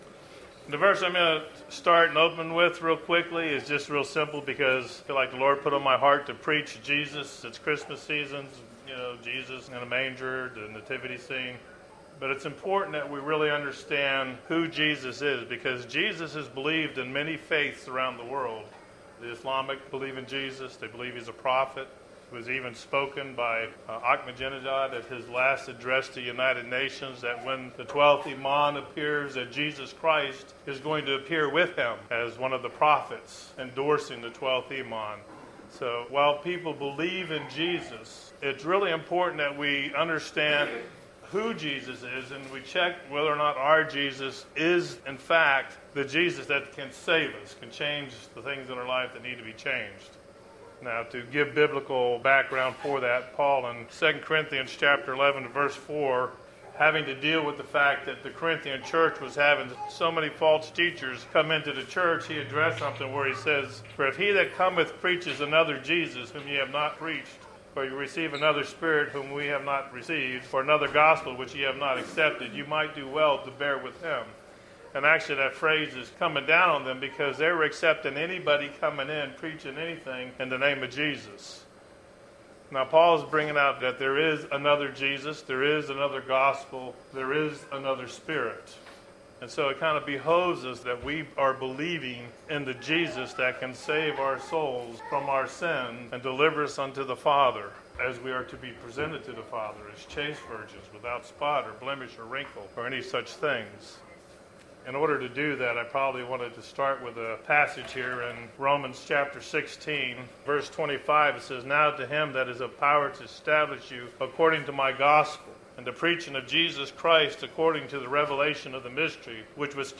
Sadly this is the last message from the Coffee Shop since the restaurant is closing due to the economy.